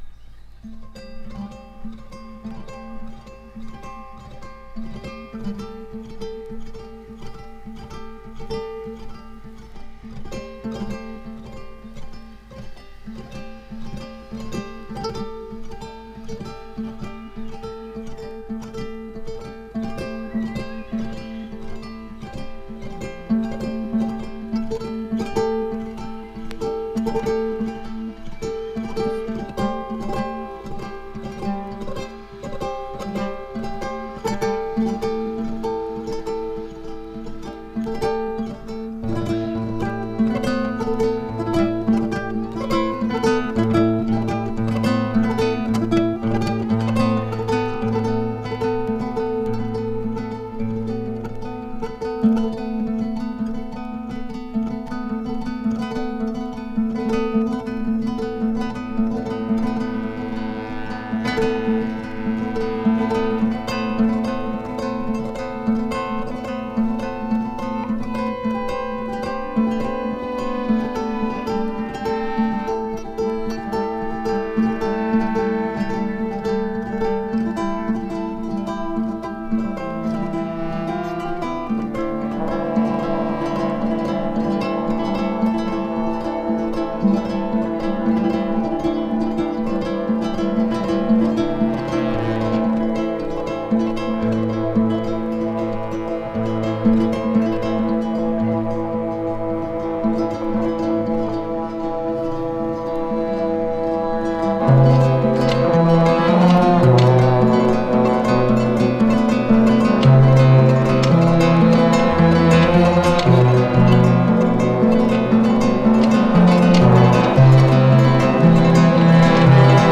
フォルクローレのようなギターととろけるようなコーラスが交わり非常にドリーミーなサウンドが味わえる。